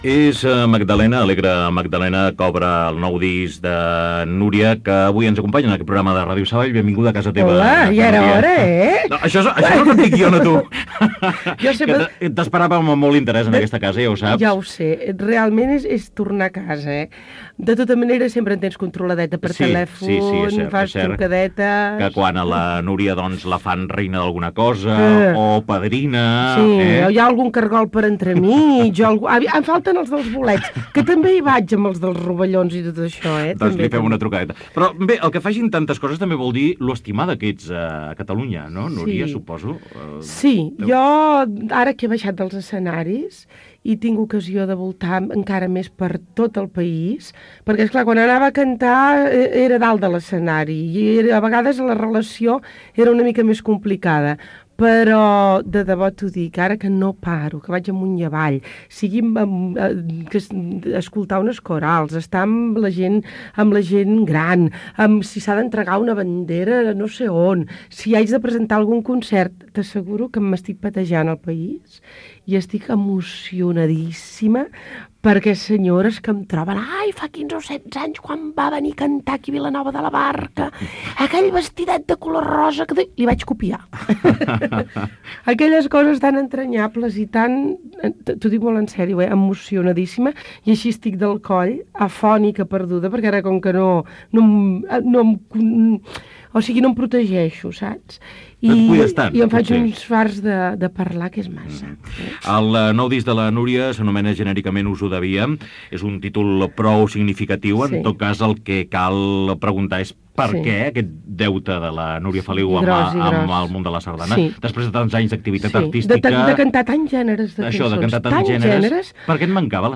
Fragment d'una entrevista a l acantant Núria Feliu amb motiu de l'edició del seu disc de sardanes "Us ho devíem"
Entreteniment